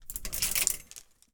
household
Cloth Hanger Movement